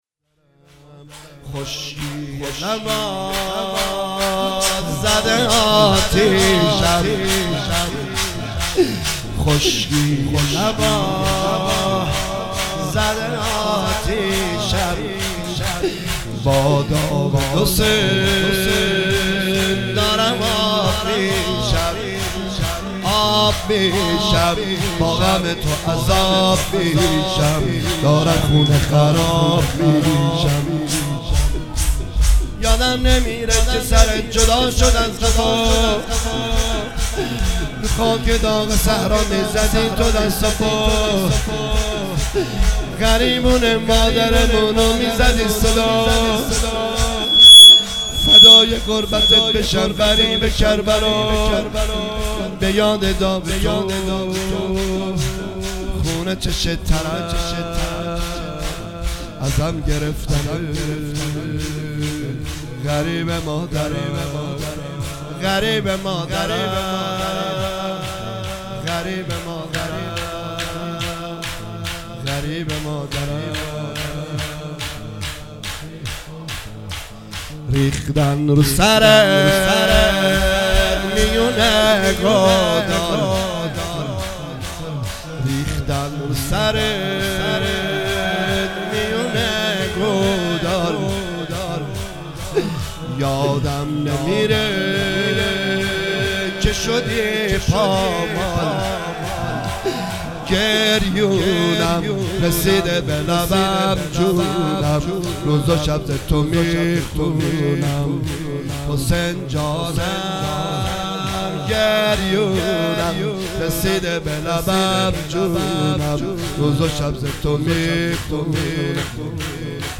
مداحی
دهه اول محرم، هیئت رزمندگان مکتب الحسین(ع)